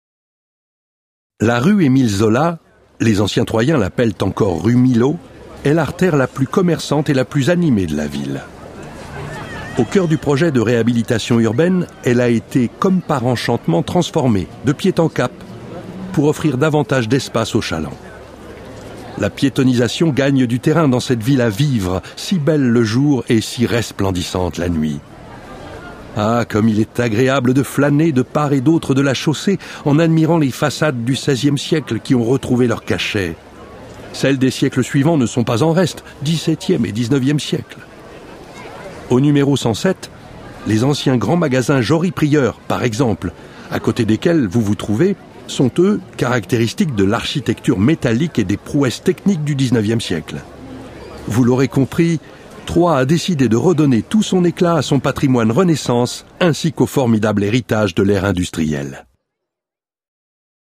Cette balade audio-guidée vous permet de découvrir par vous-même tous les lieux importants de la ville de Troyes, tout en bénéficiant des explications de votre guide touristique numérique.